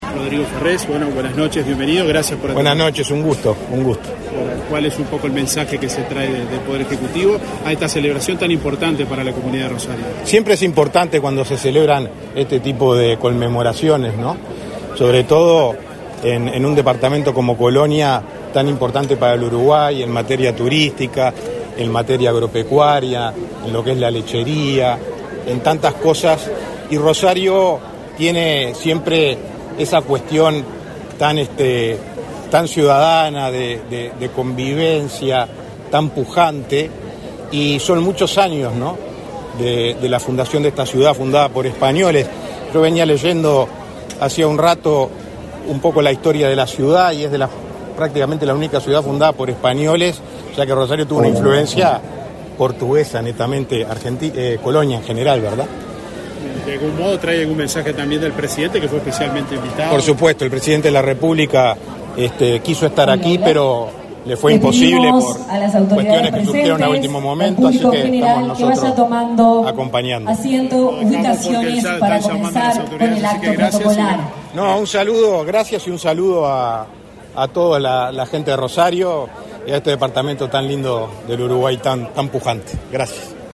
Declaraciones a la prensa del secreatrio de la Presidencia, Rodrigo Ferrés
El secretario de la Presidencia, Rodrigo Ferrés, participó, este 24 de enero, en el acto de conmemoración de los 250 años de la ciudad de Rosario.